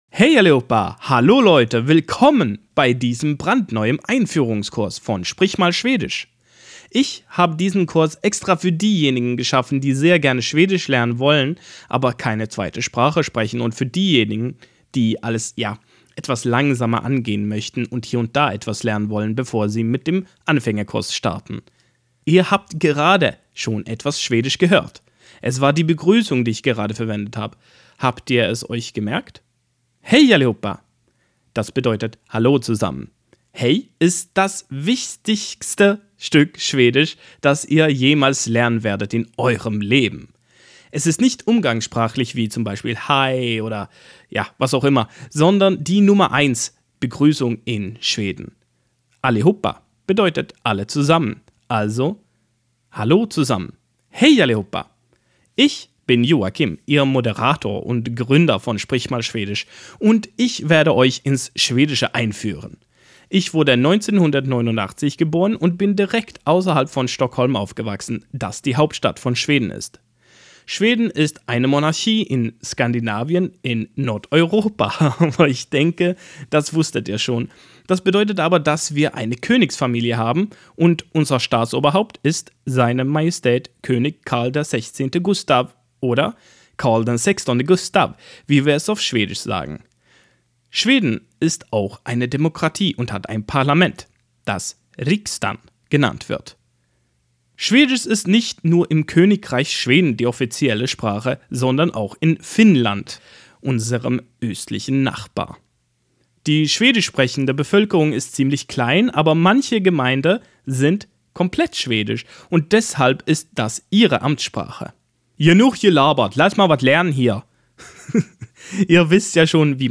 [siis-tip-box]Im gesprochenen Schwedischen wirst du jag meist als /ja/ ausgesprochen hören und är als /e/ oder /ä/.